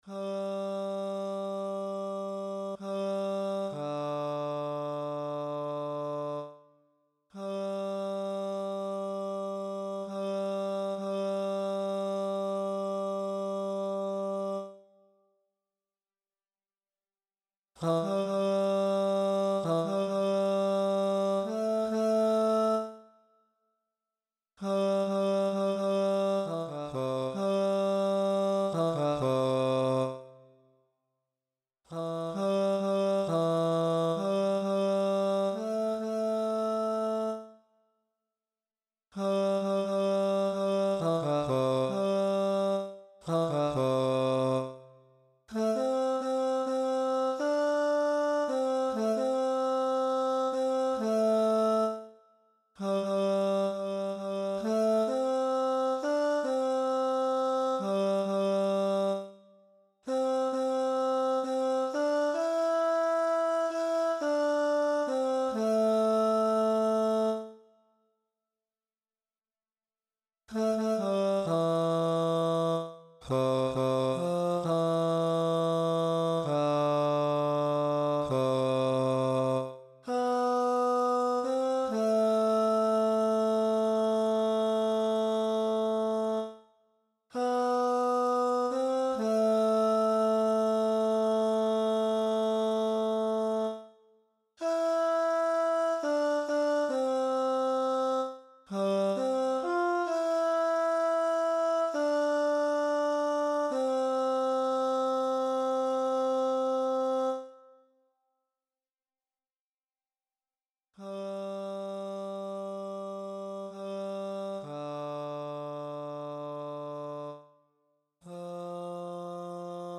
Come What May Tenor | Ipswich Hospital Community Choir